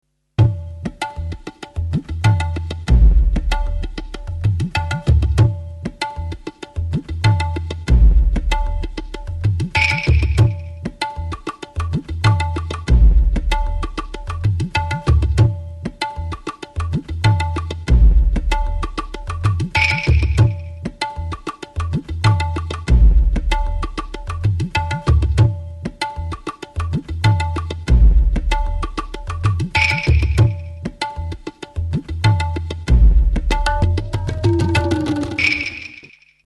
De so indeterminat
El so es produeix picant (en aquest cas amb la mà o els dits), i el so que sentim sempre és el mateix (fa ritmes, però no pot fer notes).
El so es produeix percudint la membrana.
bongos
percussio.mp3